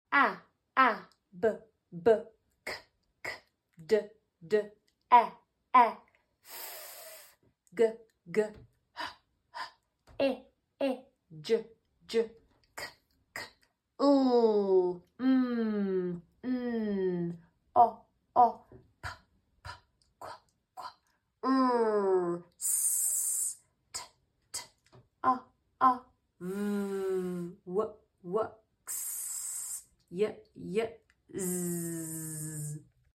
All the simple sounds in English! The first step to reading is learning these sounds. As soon as your child knows the first 5 (m,a,s,d,t), then they can start reading with the sounds that they know.